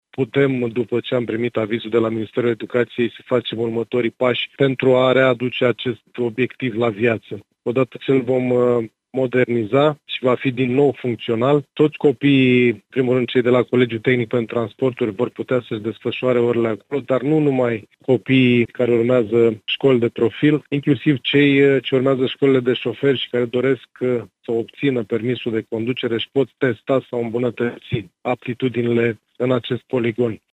Primarul Adrian Niţă a declarat că, începând din primăvara viitoare, vor fi făcute studiile de fezabilitate şi documentaţia tehnică.